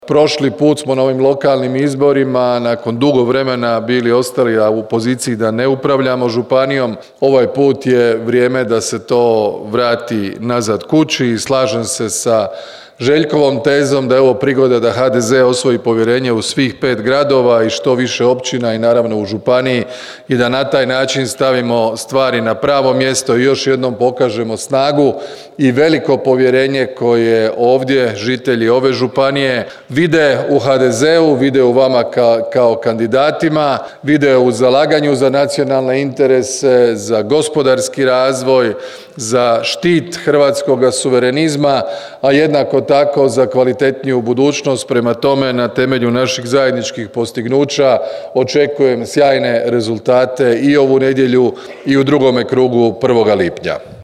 Drniški HDZ proslavio 35. obljetnicu osnutka te održao predizborni skup
Kazao je Plenković u Drnišu i zaključio: